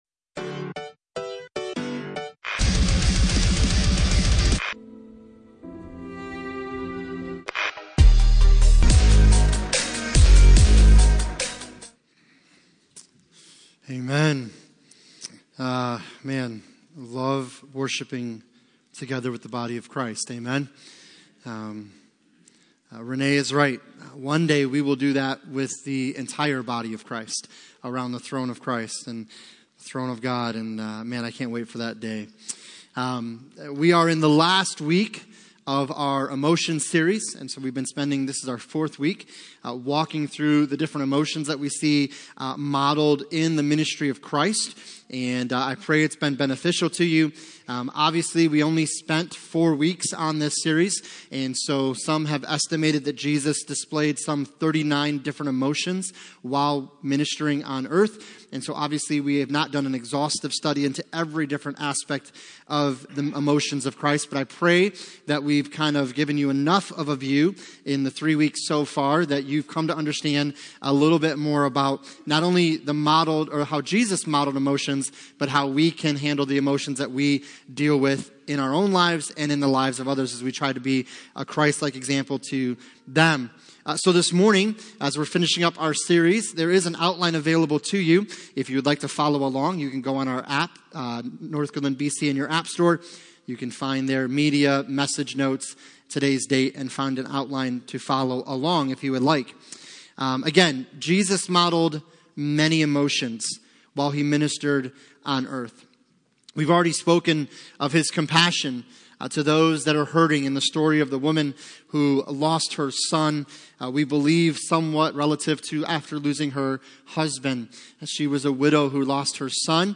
Passage: Matthew 21:12-13 Service Type: Sunday Morning